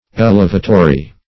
Meaning of elevatory. elevatory synonyms, pronunciation, spelling and more from Free Dictionary.
Search Result for " elevatory" : The Collaborative International Dictionary of English v.0.48: Elevatory \El"e*va`to*ry\, a. Tending to raise, or having power to elevate; as, elevatory forces.